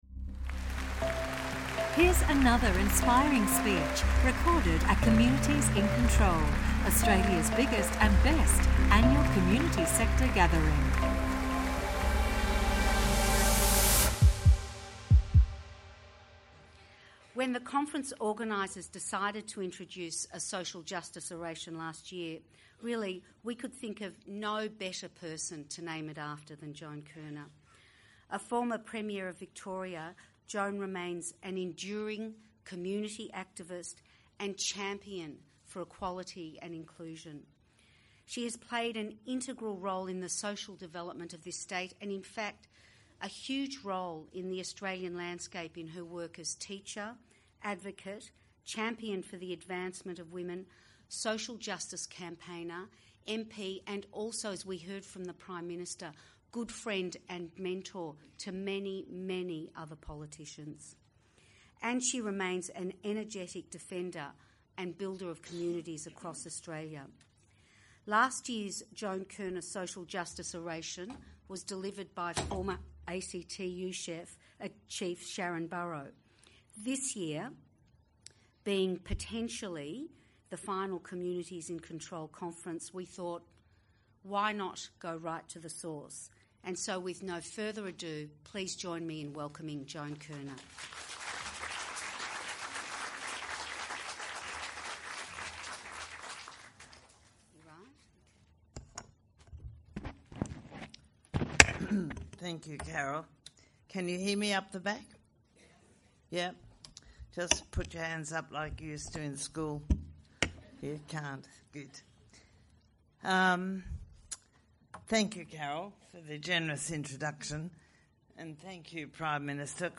Australian legend of stage and screen, Magda Szubanki, gave a heartfelt account of her community work background, her childhood and of the important of social cohesion at the 2016 Communities in Control Conference